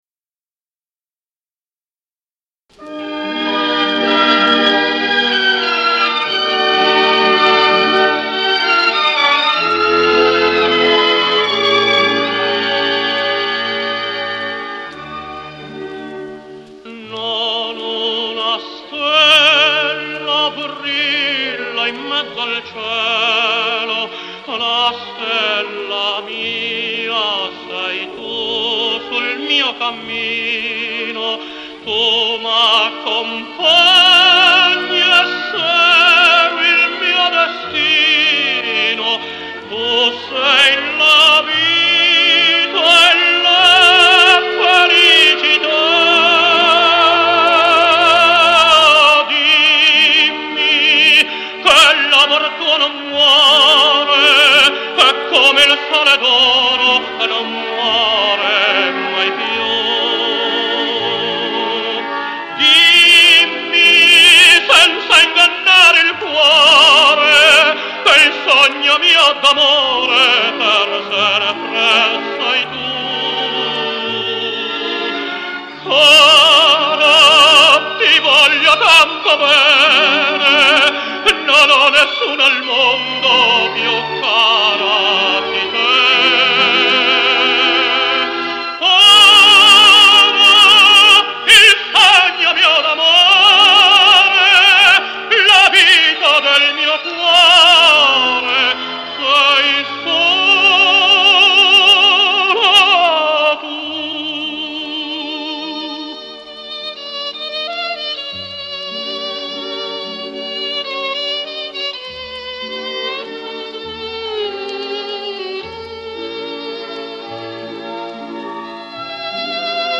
con accompagnamento d'orchestra